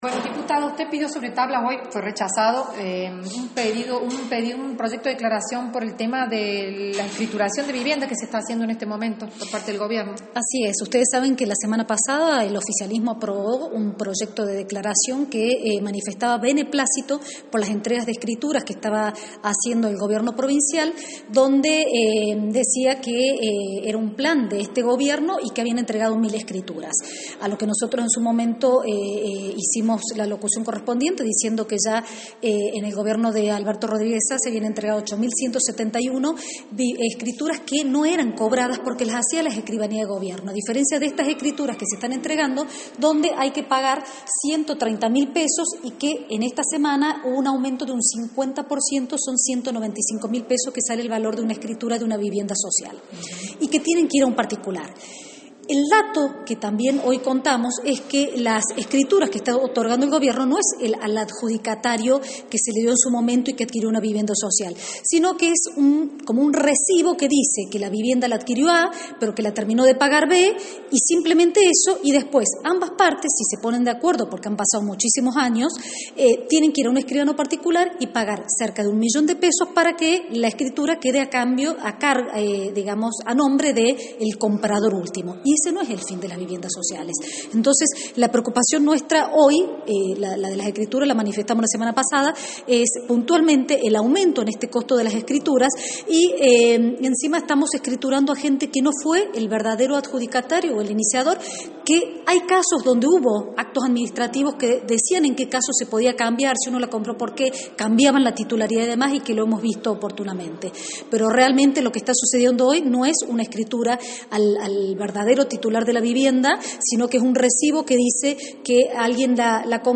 La diputada del bloque opositor, Silvia Sosa Araujo, justificó con vehemencia los proyectos presentados sobre tablas que fueron rechazados sin tratamiento por el oficialismo y los legisladores aliados, entre ellos un pedido de la interpelación al ministro de Hacienda y Obras Públicas, Néstor Ordoñez, con el objetivo de conocer cuánto dinero tiene la Provincia.
Este miércoles, durante una nueva acalorada sesión donde primaron los rencores y discusiones infructuosas, se presentaron diversos proyectos, entre ellos varios de iniciativa de la oposición, que fueron rechazados y pasados a comisión y a los que se refirió Sosa Araujo en una entrevista que realizó con periodistas legislativos.